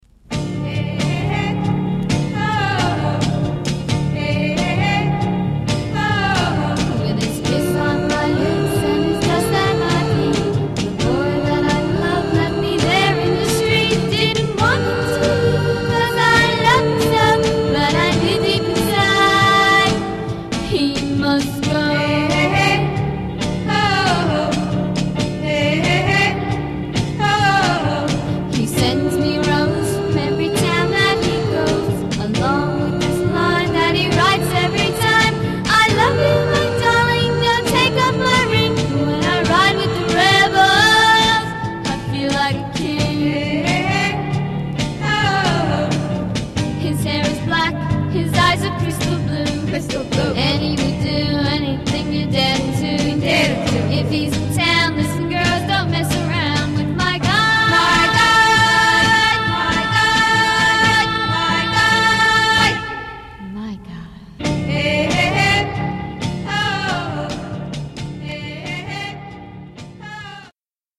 more down tempo but still just as charming